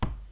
wood03.ogg